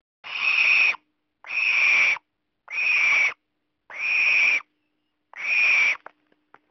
barn-owl-screaming.mp3